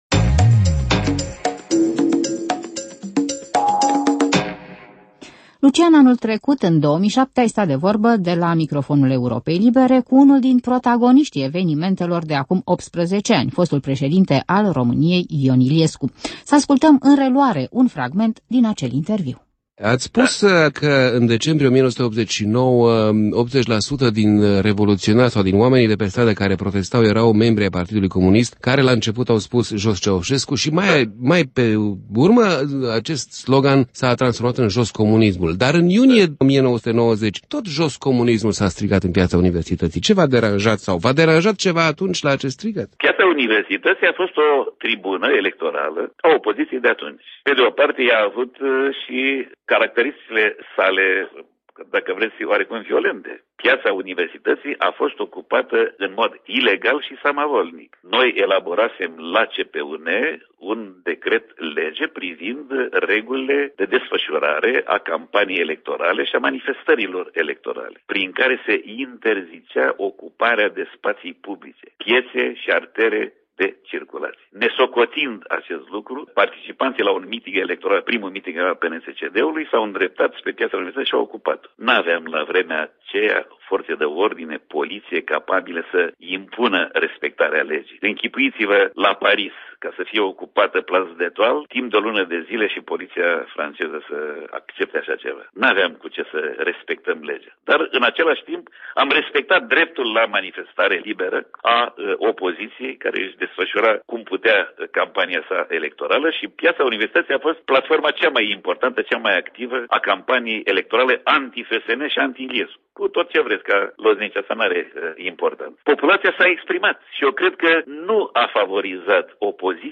Din Arhiva Europei Libere: un interviu cu Ion Iliescu despre mineriada din 1990